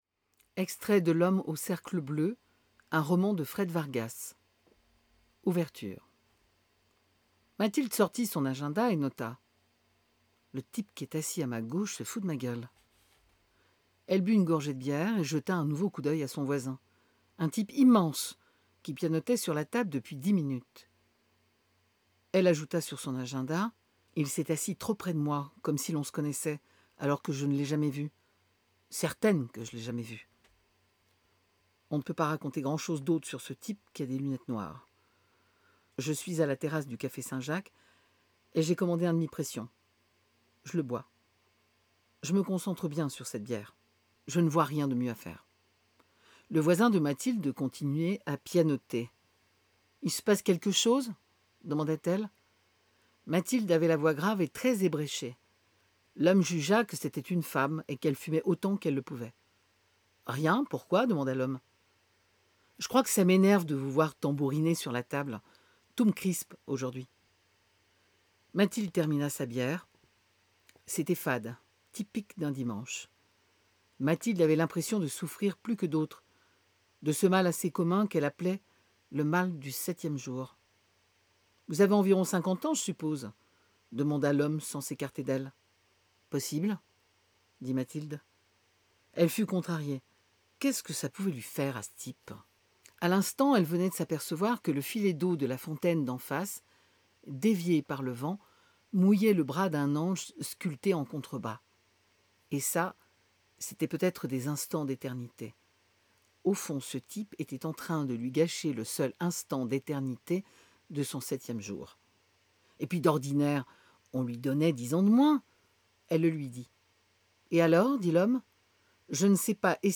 Bandes-son
46 - 52 ans - Soprano